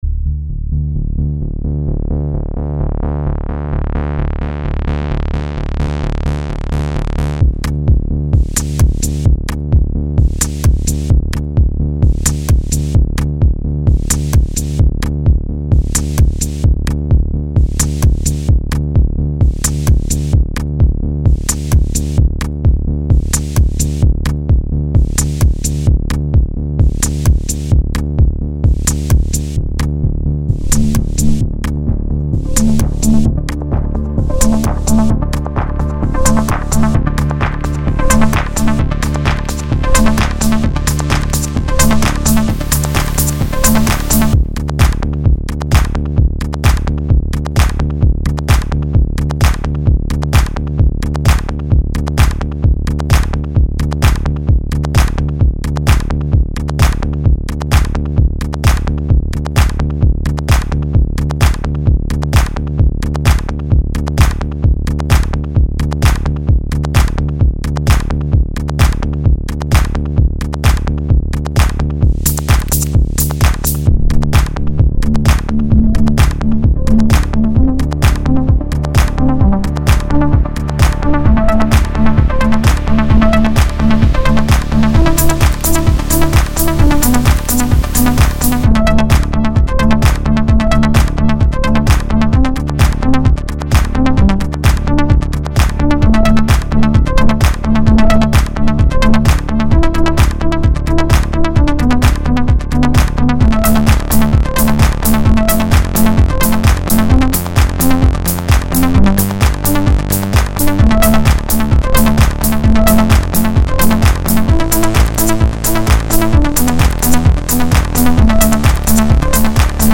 Genre House